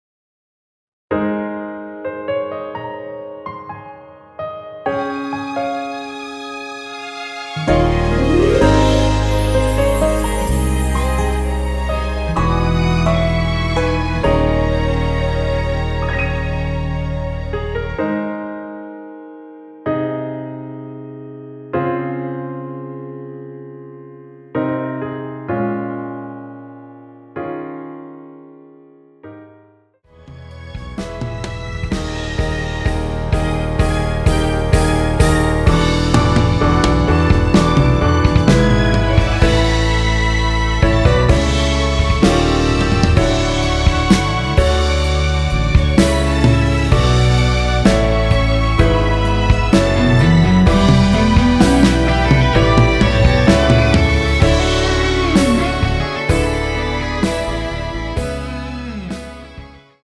원키에서(+5)올린 MR입니다.(미리듣기 확인)
Ab
앞부분30초, 뒷부분30초씩 편집해서 올려 드리고 있습니다.
중간에 음이 끈어지고 다시 나오는 이유는